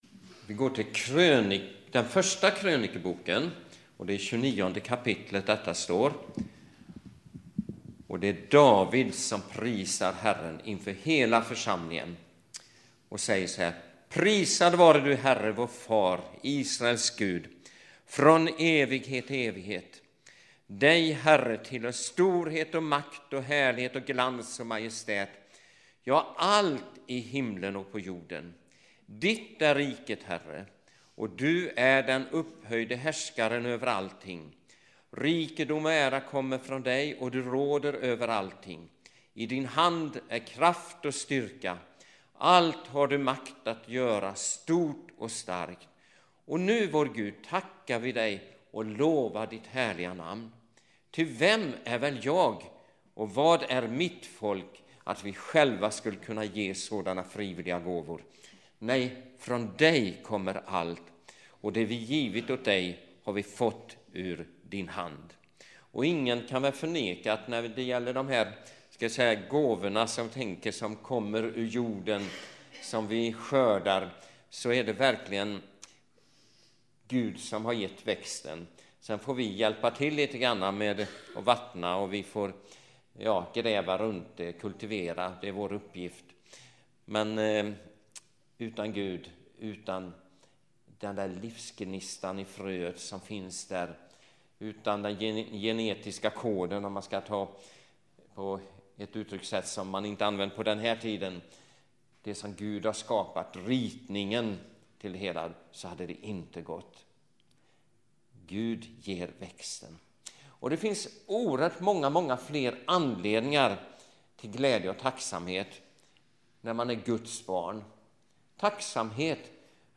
– Korskyrkan i Mölndal
2012-10-14 Predikan av